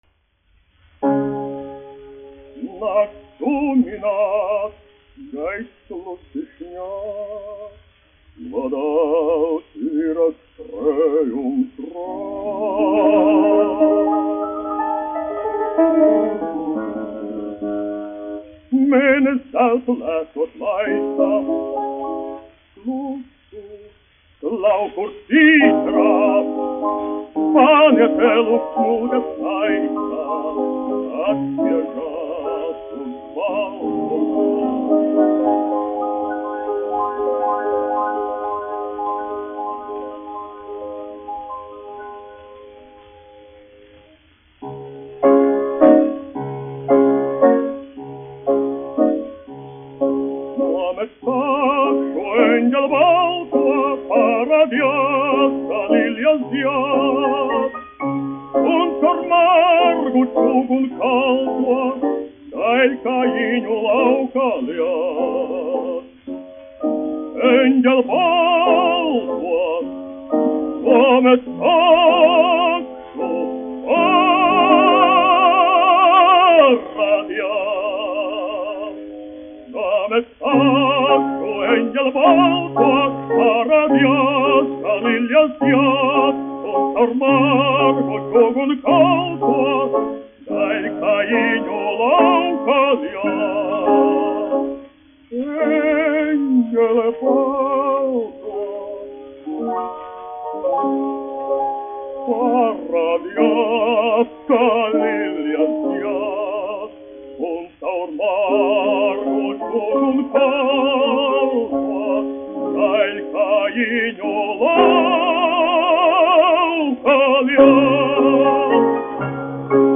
1 skpl. : analogs, 78 apgr/min, mono ; 25 cm
Dziesmas (zema balss) ar klavierēm
Latvijas vēsturiskie šellaka skaņuplašu ieraksti (Kolekcija)